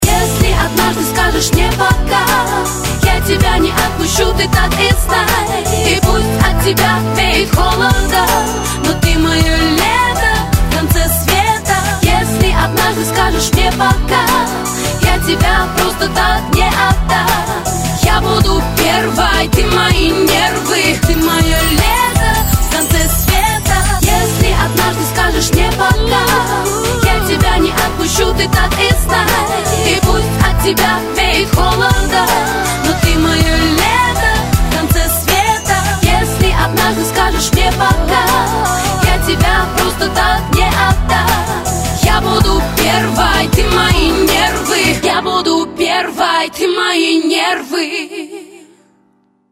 Категория: Rap, RnB, Hip-Hop